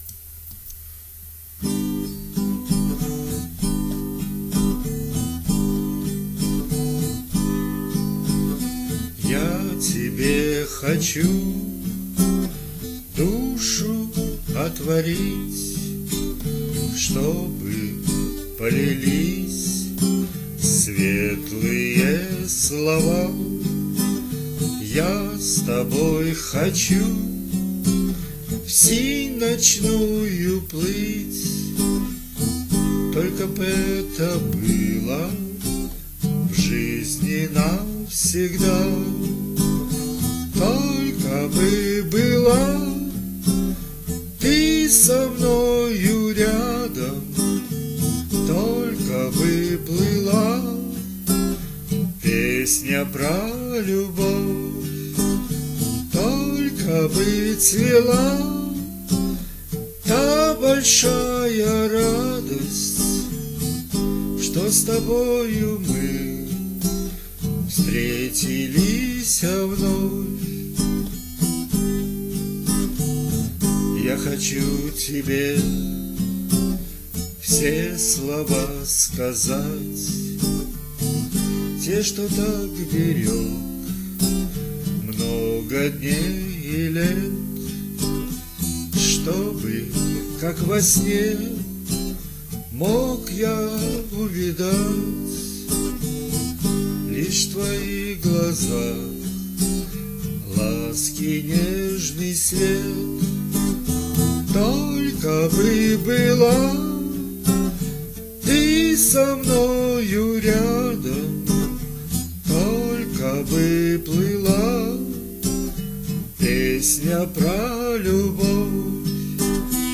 Музыкальный хостинг: /Авторская песня